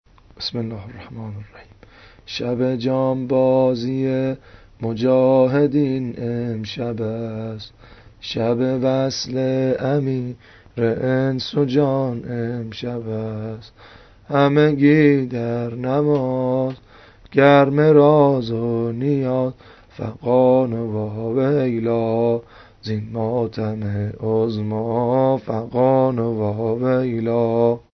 نوحه-شب عاشورا
دانلود سبک